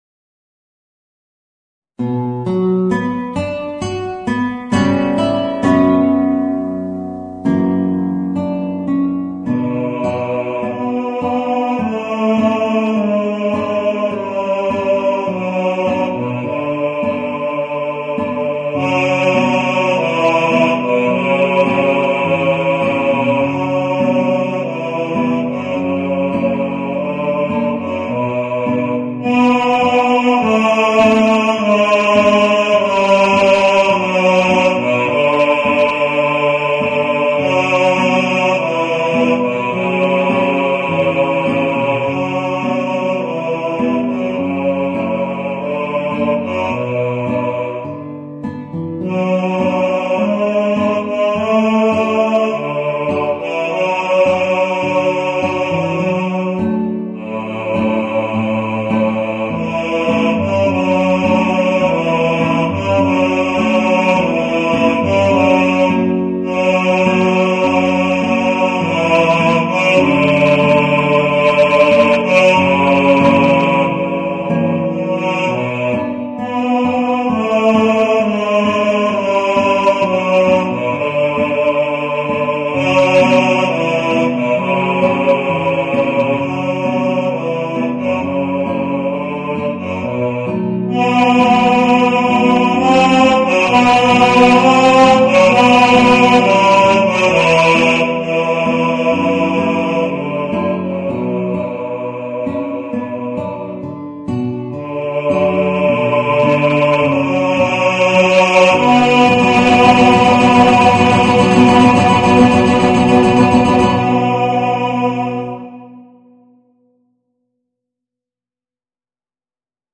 Voicing: Guitar and Bass